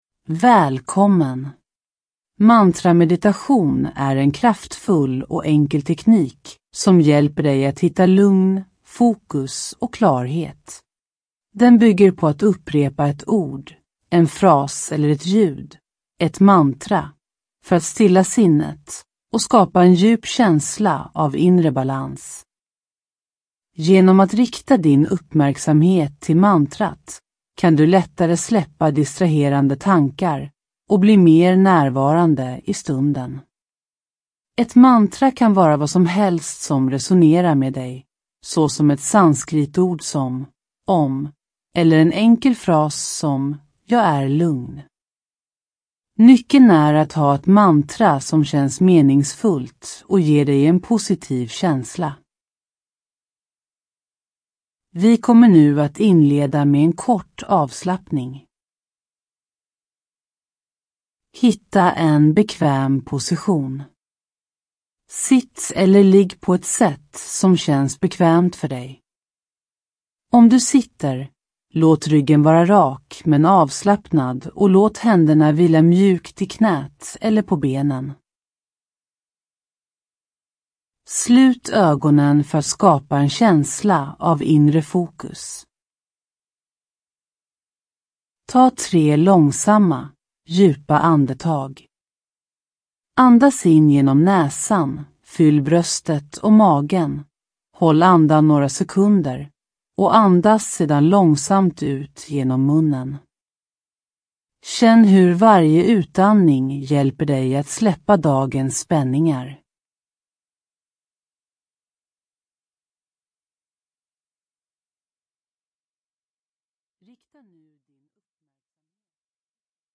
Ljudbok
Denna guidade meditation leder dig genom en avslappnande resa där du: